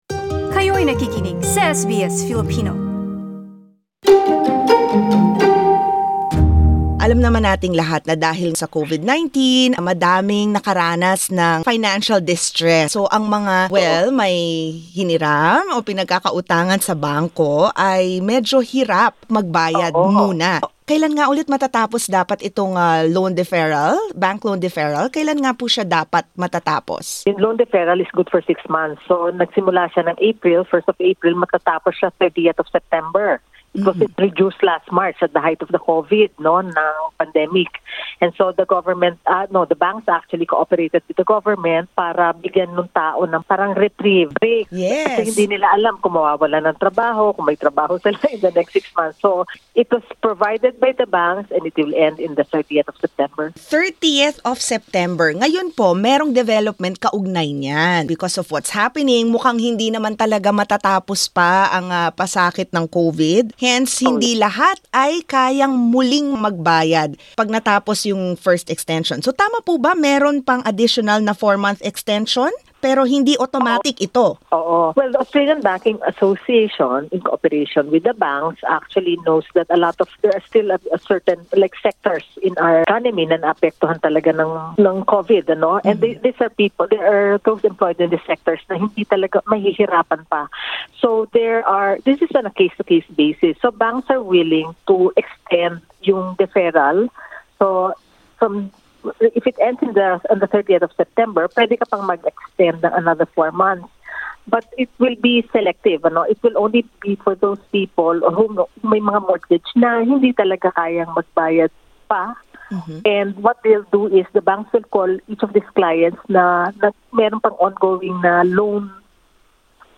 A finance broker shares what you need to know about your bank loan during these financially difficult times.